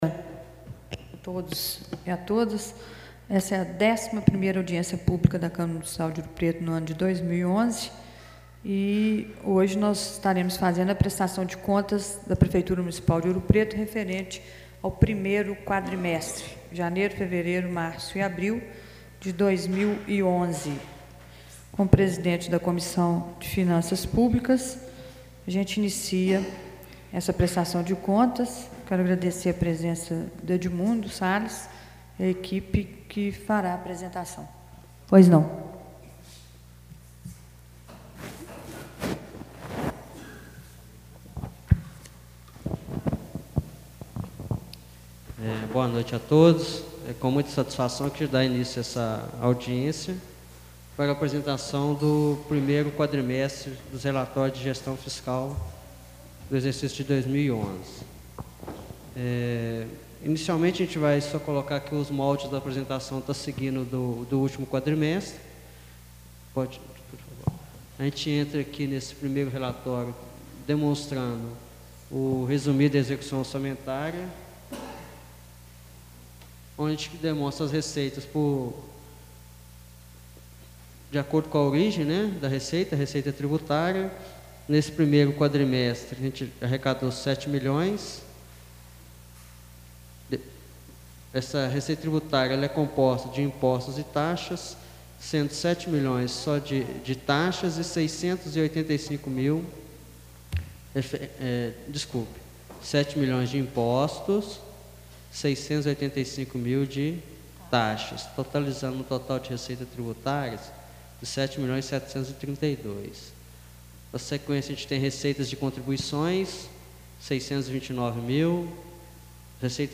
Áudio: | Câmara Municipal de Ouro Preto Audiência Pública sobre Prestação de Contas da Prefeitura Municipal de Ouro Preto, referente ao 1° quadrimestre de 2011 (janeiro, fevereiro, março e abril/2100) Reunião Compartilhar: Fechar